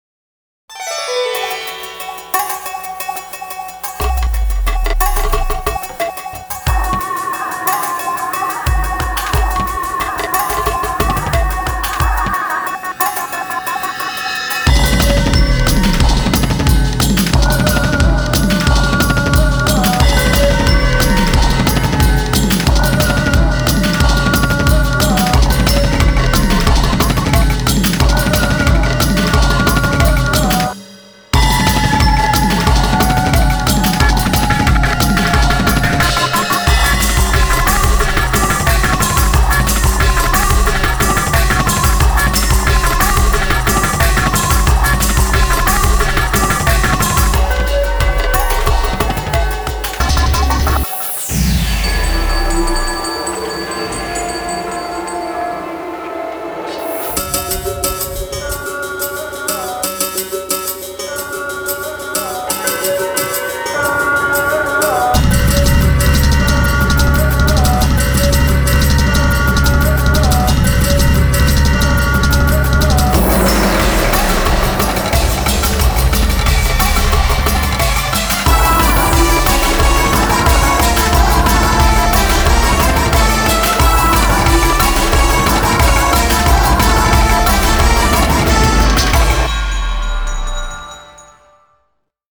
BPM165-180
Audio QualityMusic Cut